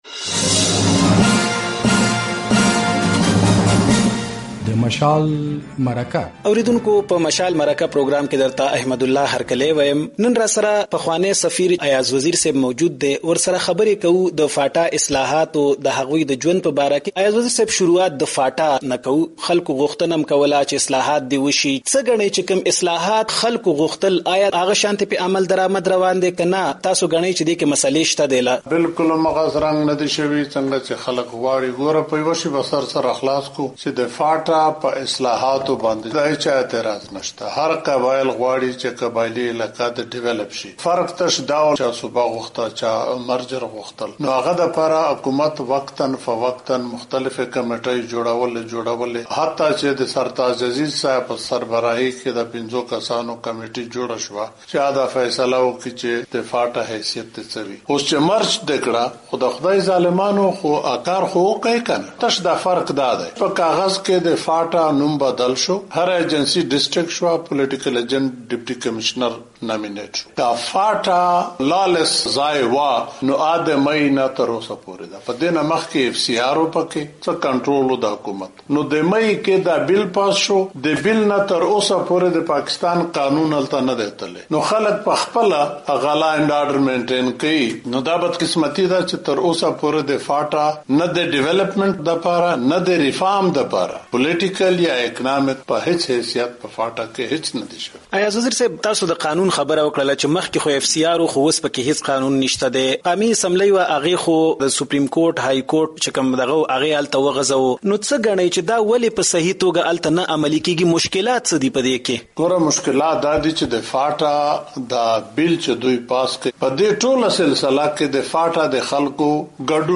له ایاز وزیر سره د مشال مرکه